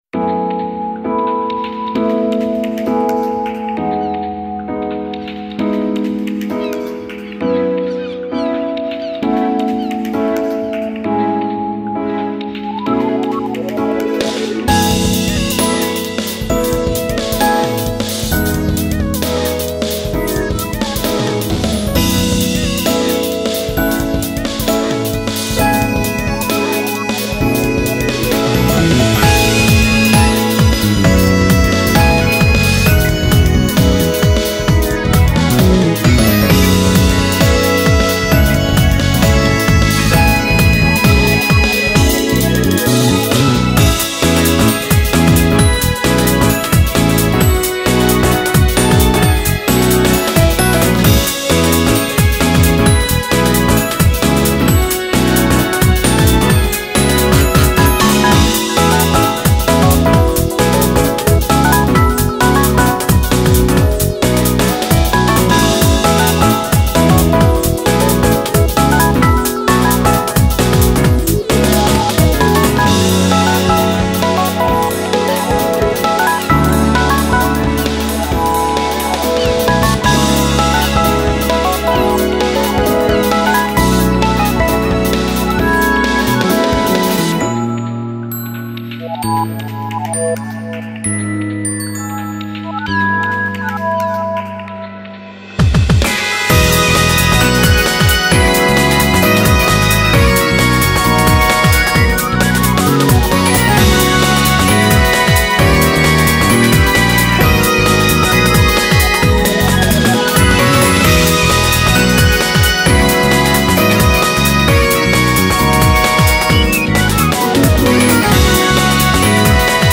BPM66-132
Audio QualityCut From Video